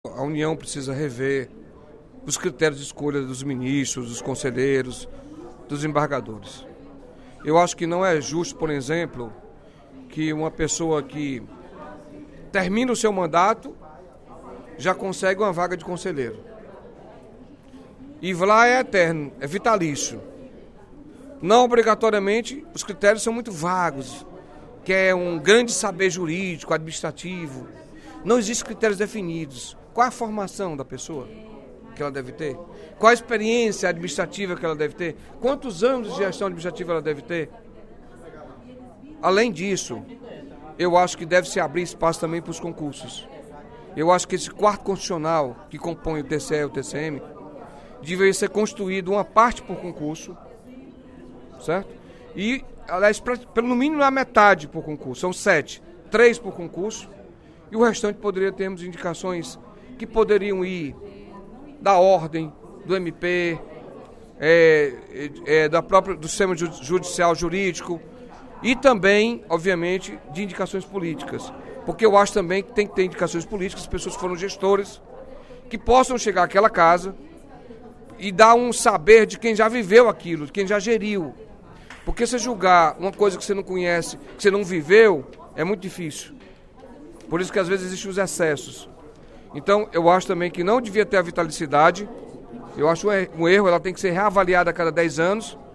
O deputado Carlos Felipe (PCdoB) criticou, durante pronunciamento no primeiro expediente da sessão plenária desta quarta-feira (14/06), a forma como são escolhidos os membros de tribunais e cortes de contas do País.